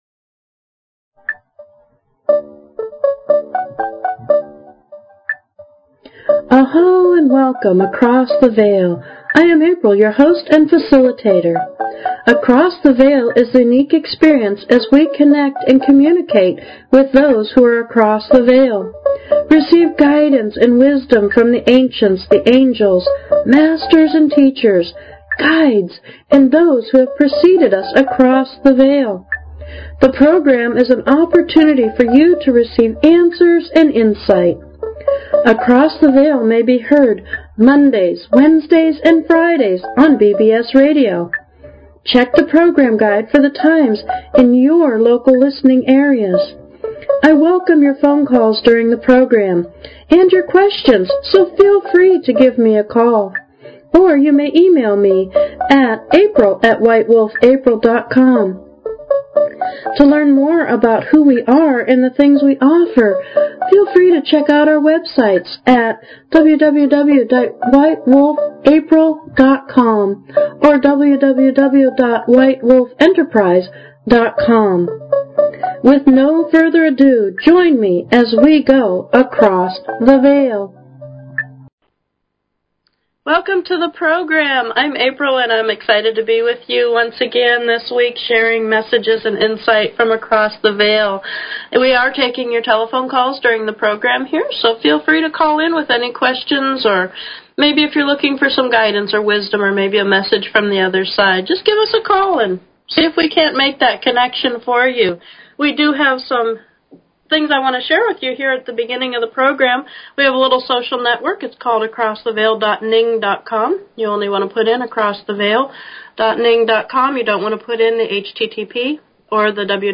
FREE Intuitive Readings Every Week, Every Show, For Every Call-In Across the Veil Please consider subscribing to this talk show.
Talk Show